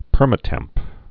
(pûrmə-tĕmp)